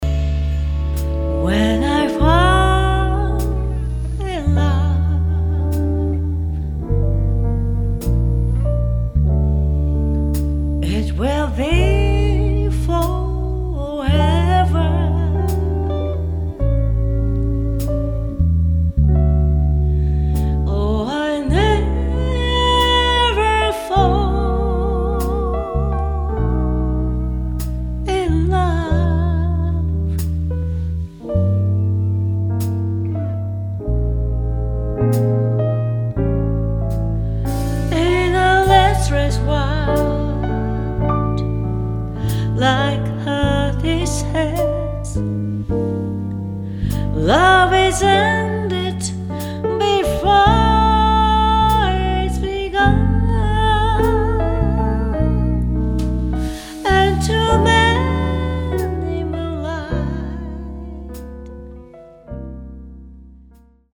ジャズの枠を超え、ラテン、ファンク、ロック、レゲエなど
あらゆるビートのエッセンスを取り込んだアンサンブル。
Vocal
Guitar
Bass
Piano